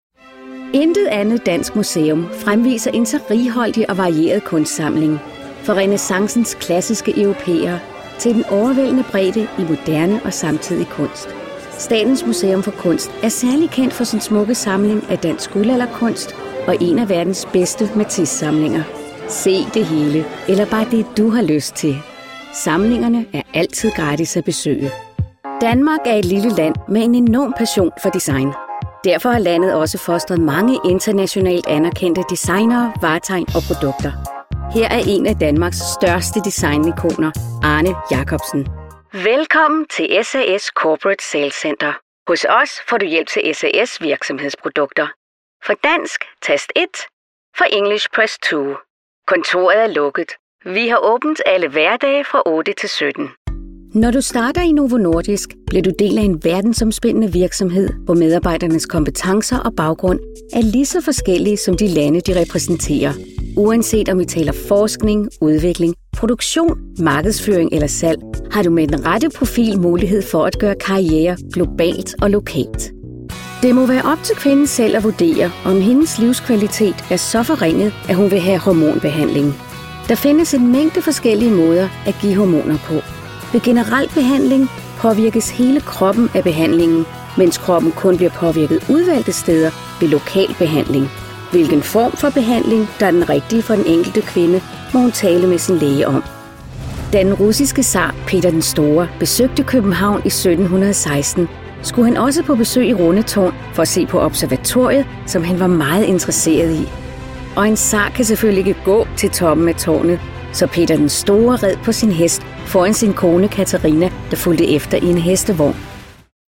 Jeg taler dansk og med min accent, mit engelsk er International / Trans-Atlantic engelsk.
Sprechprobe: Sonstiges (Muttersprache):
My voice is warm, sincere, sophisticated and intelligent.